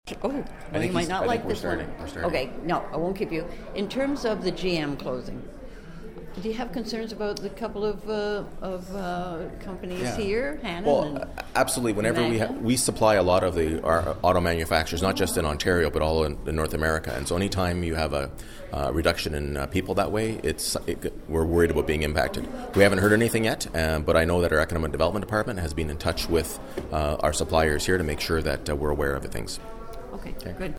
Belleville mayor-elect Mitch Panciuk spoke to Quinte News about concerns regarding how it might affect auto supply companies in Belleville.